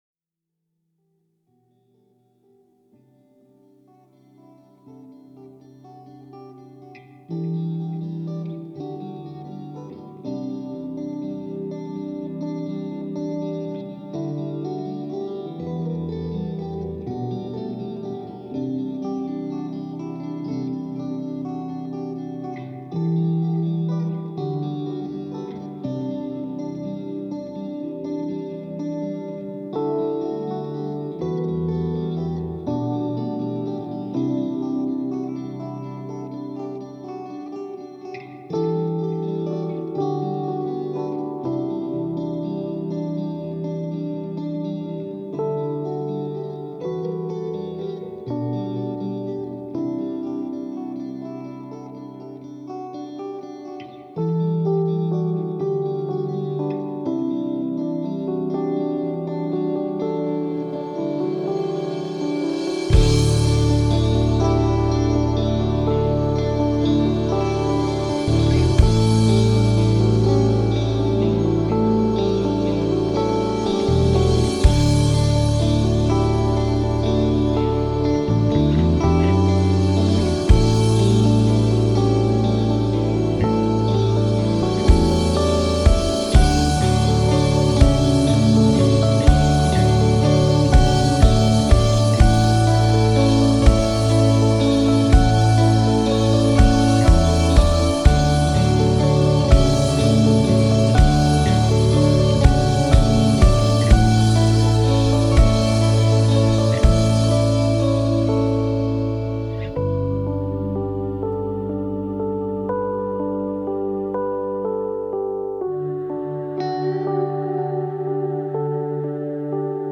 a post-rock band formed in April 2011